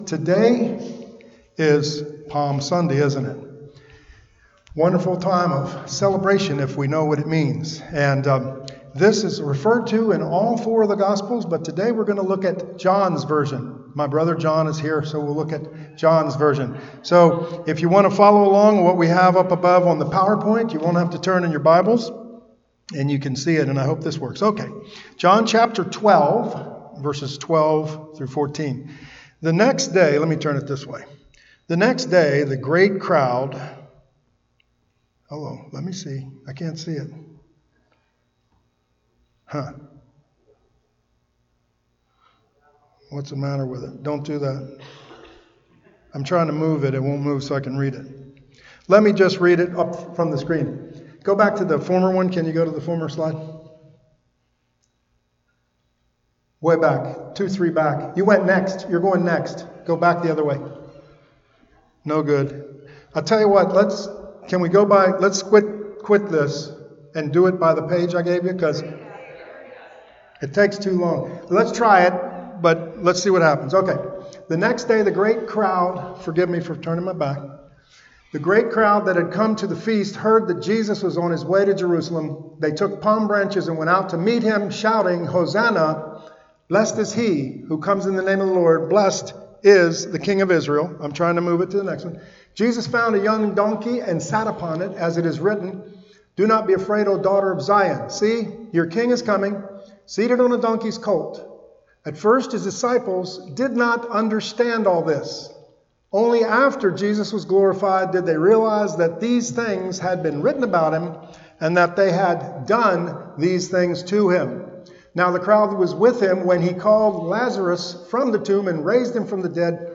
This was today’s message (audio version) at Beverly Hills Community Church. It’s a Palm Sunday message about Welcoming the King, our Lord Jesus Christ.